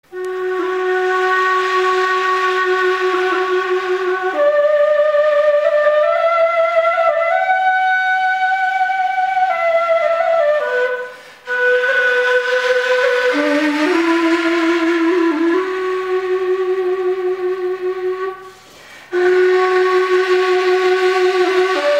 Соло игра на курае